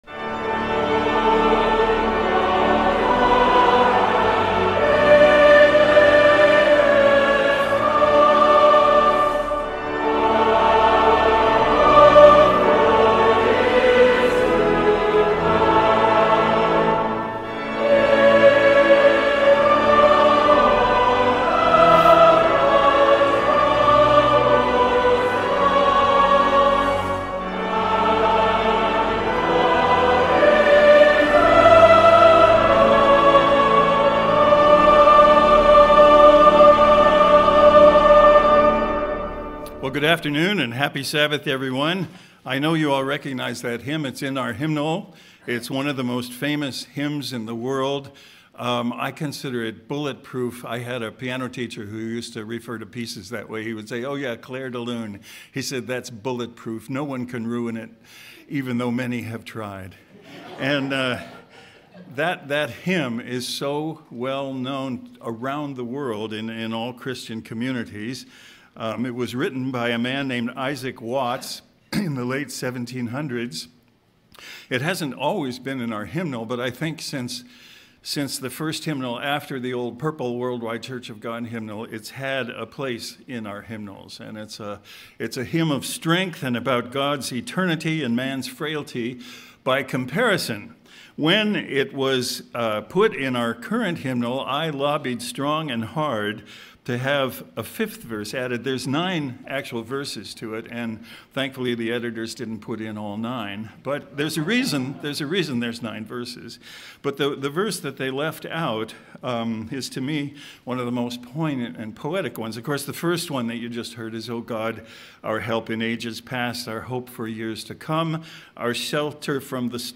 Given in Cleveland, OH
sermon_the_beauty_of_the_lord.mp3